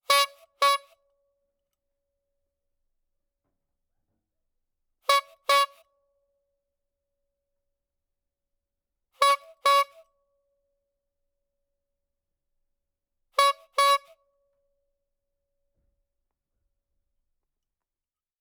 Звуки анимации
Металлические стуки